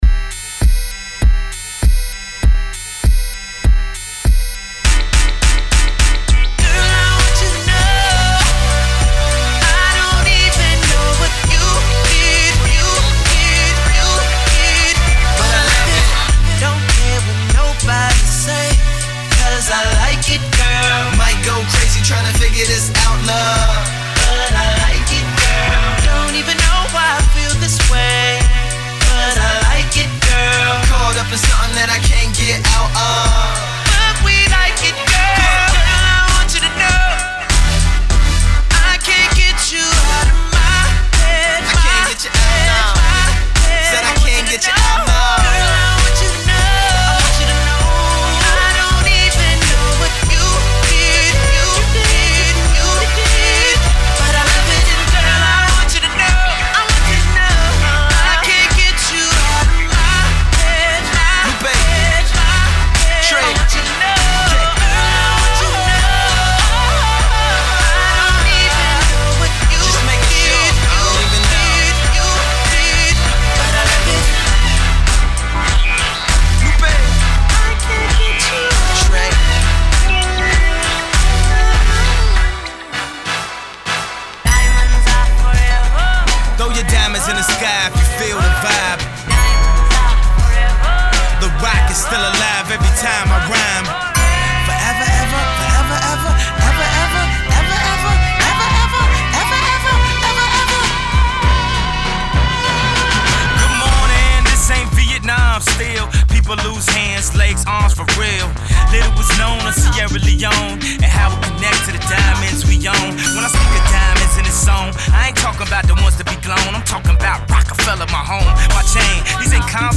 Medley style mix
Club Mix